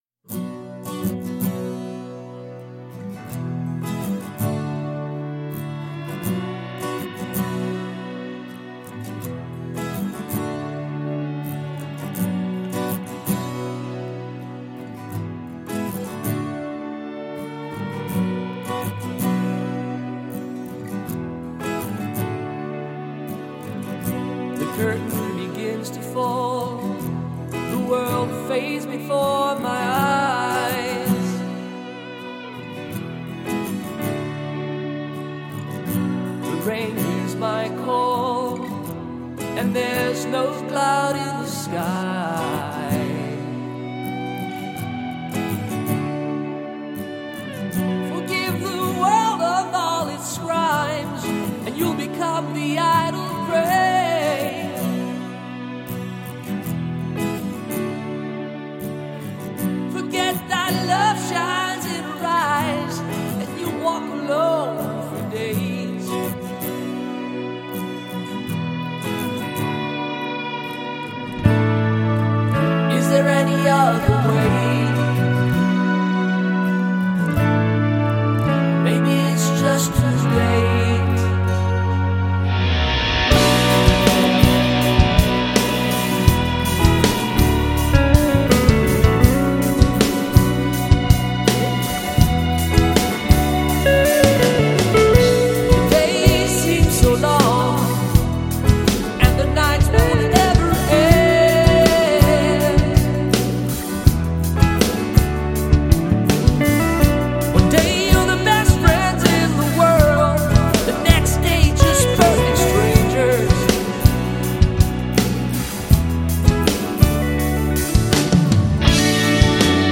regal brand of prog rock